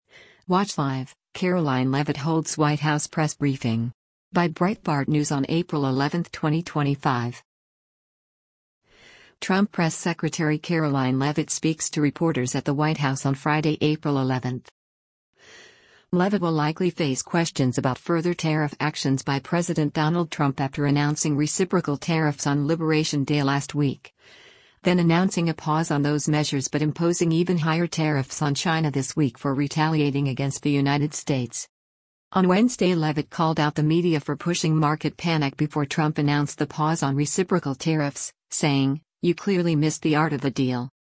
Trump Press Secretary Karoline Leavitt speaks to reporters at the White House on Friday, April 11.